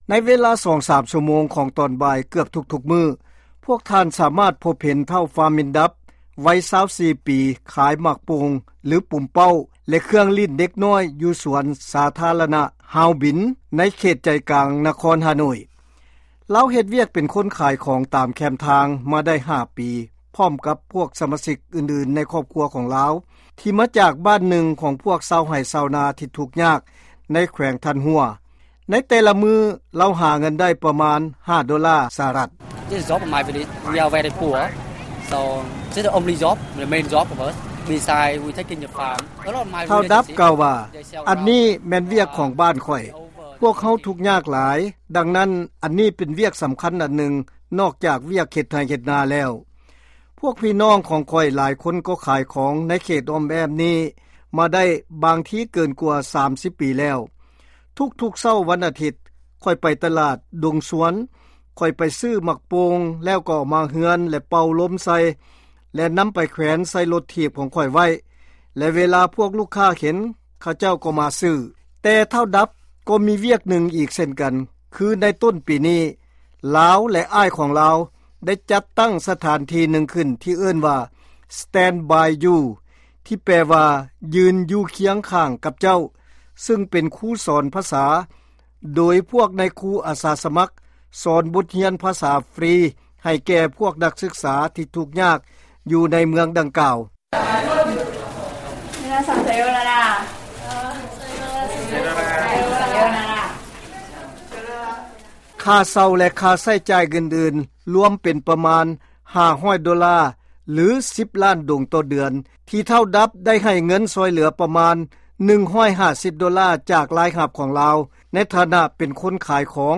ຟັງລາຍງານນີ້ ເປັນພາສາອັງກິດຊ້າໆ: